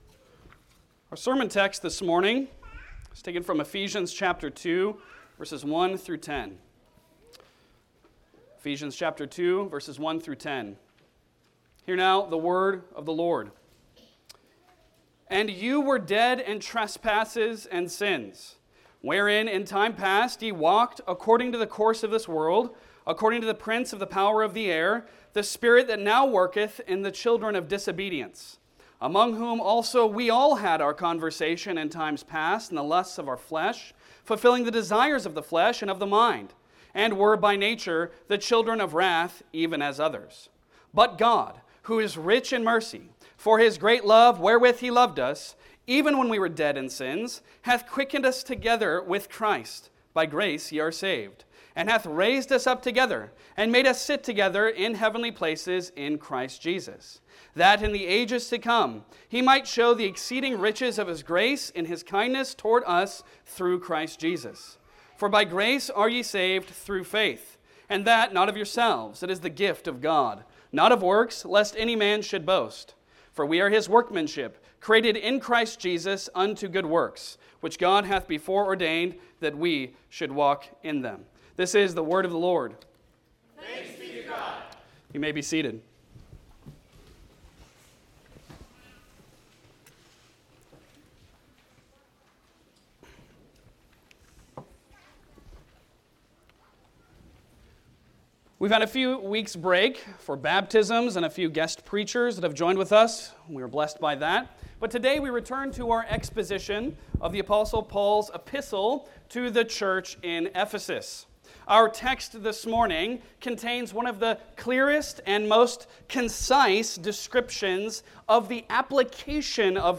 Passage: Ephesians 2:1-10 Service Type: Sunday Sermon